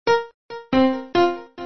piano nē 69
piano69.mp3